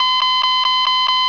metaldetector.wav